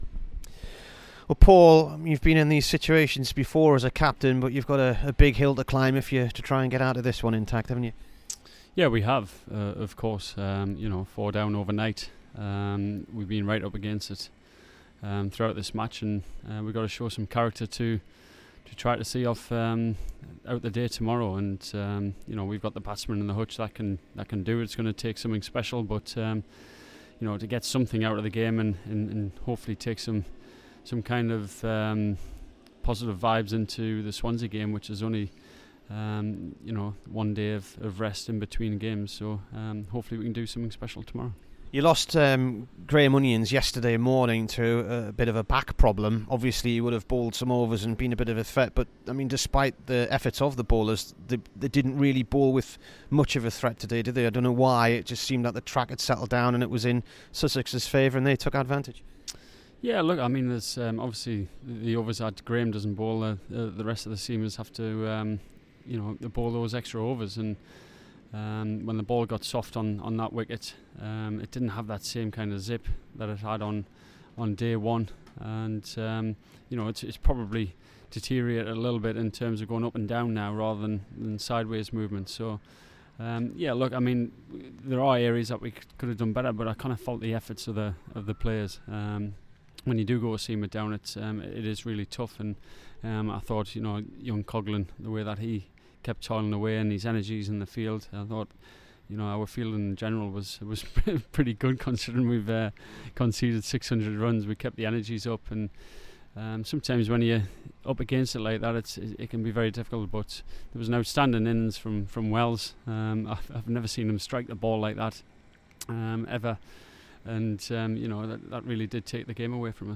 PAUL COLLINGWOOD INT
Here is the Durham skipper after day 3 v Sussex in Hove.